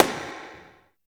50 SNARE 3-L.wav